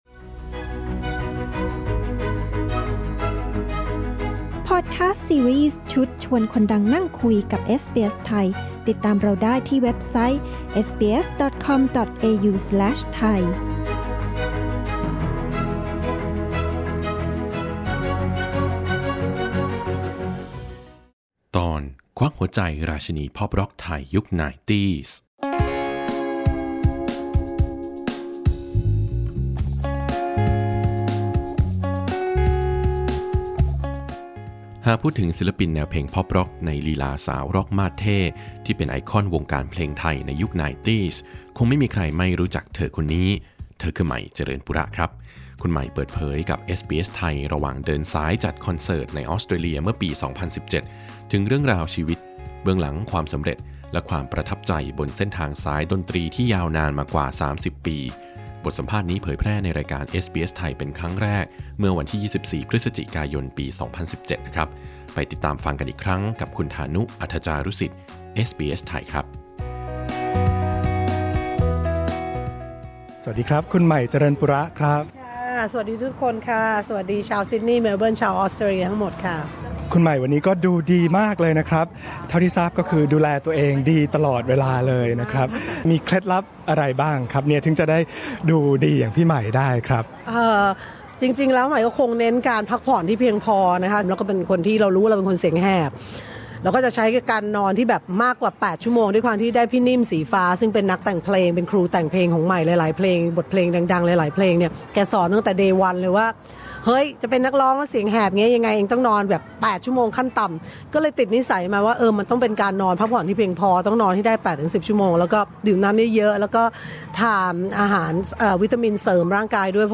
หากพูดถึงศิลปินแนวเพลงป็อปร็อก ในลีลาสาวร็อคมาดเท่ห์ ไอคอนวงการเพลงไทยยุค 90 คงไม่มีใครไม่รู้จัก ใหม่ เจริญปุระ คุณใหม่เปิดเผยกับ เอสบีเอส ไทย ระหว่างเดินสายจัดคอนเสิร์ตในออสเตรเลีย เมื่อปี 2017 ถึงเรื่องราวชีวิต เบื้องหลังความสำเร็จ และความประทับใจบนเส้นทางสายดนตรีที่ยาวนานมากว่า 30 ปี บทสัมภาษณ์นี้เผยแพร่ในรายการเอสบีเอส ไทย ครั้งแรก เมื่อวันที่ 24 พ.ย. 2017 นี่เป็นหนึ่งเรื่องราวจากพอดคาสต์ ซีรีส์ ชุด “ชวนคนดังนั่งคุย” ของเอสบีเอส ไทย